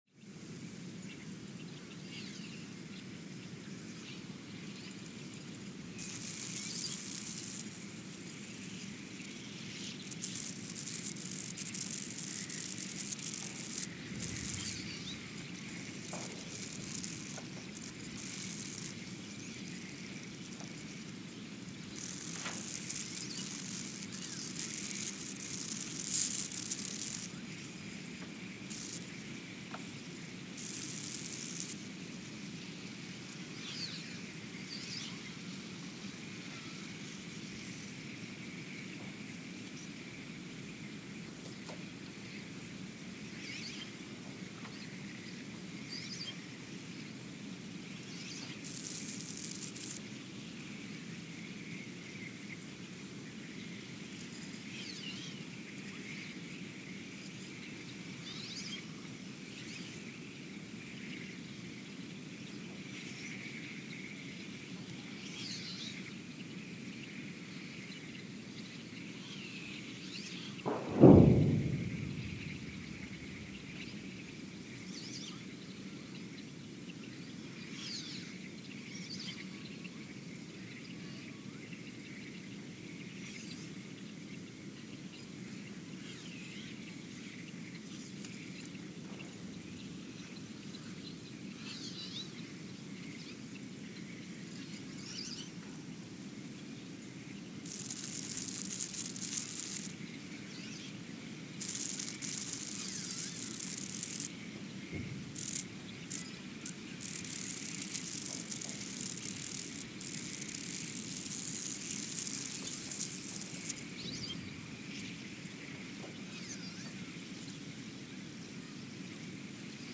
Ulaanbaatar, Mongolia (seismic) archived on June 11, 2024
Sensor : STS-1V/VBB
Speedup : ×900 (transposed up about 10 octaves)
Loop duration (audio) : 03:12 (stereo)
Gain correction : 25dB